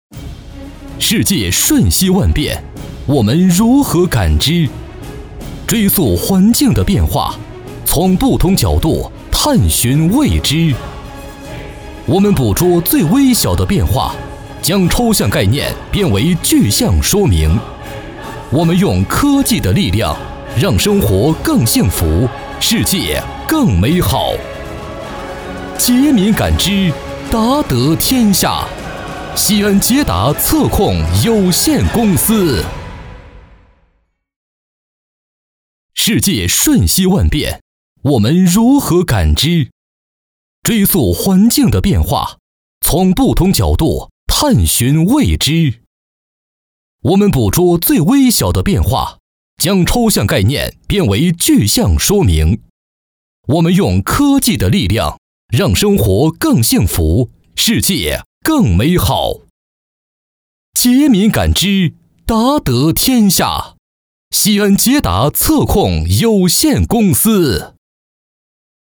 男52号配音师
专题片-男52-激情震撼有力 捷达测控公司宣传.mp3